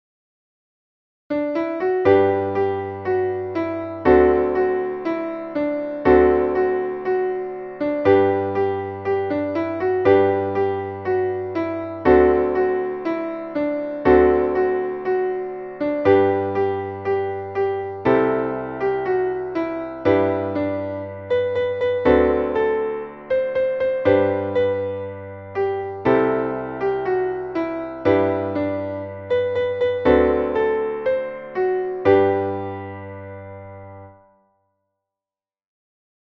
Traditionelles Kinderlied / Volkslied